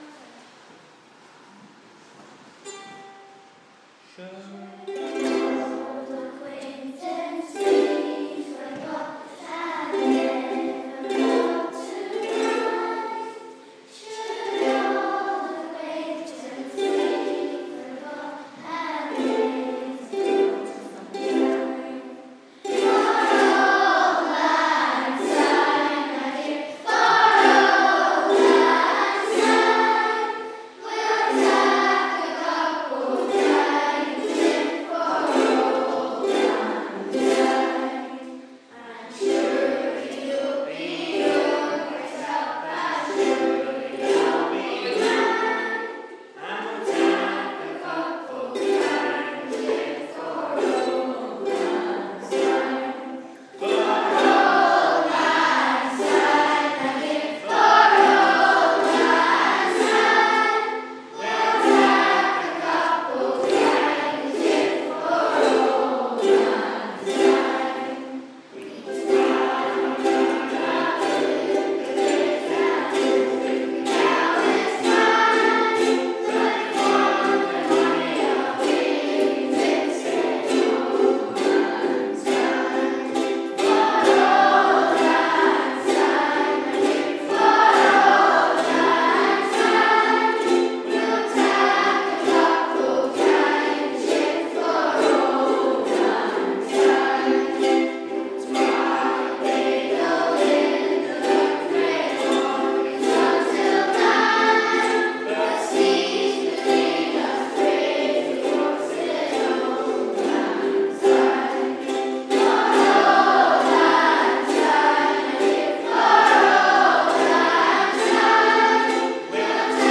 The end of a perfect day of Burns celebrations. This performance of Auld Lang Syne was at the end of a fabulous two days of Scottish Celebrations. Here we are joined by P7 in singing to close our Burns Supper.